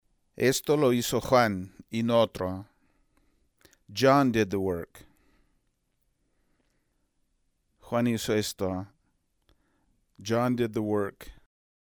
El énfasis sintáctico del español en contraste con el énfasis fonético del inglés.